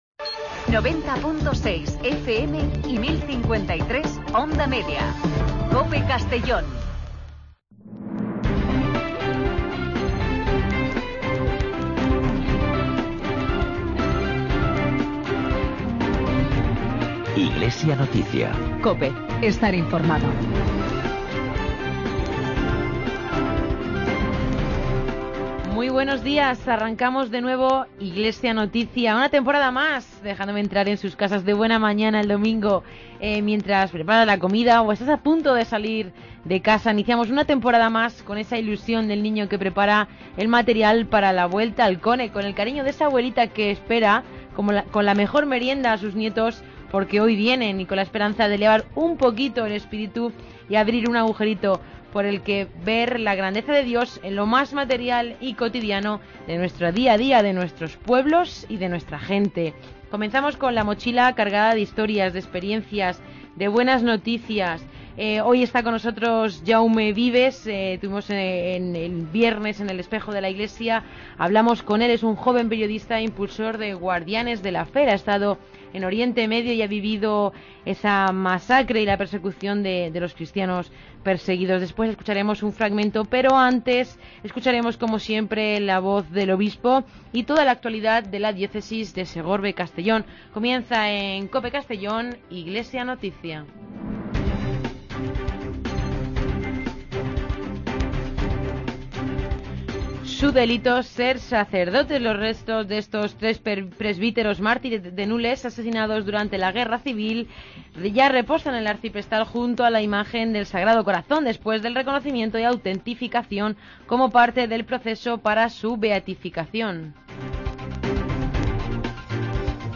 Espacio informativo de la actualidad diocesana de Segorbe-Castellón